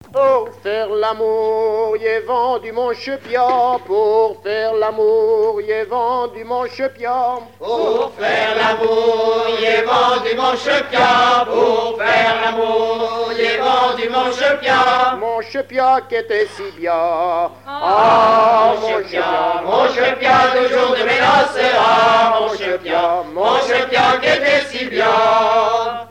Genre énumérative
Chansons traditionnelles